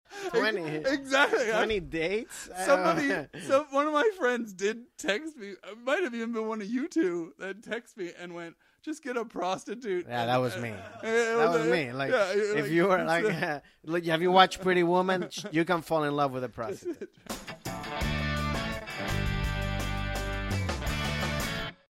Genres: Comedy, Comedy Interviews, Stand-Up
Trailer: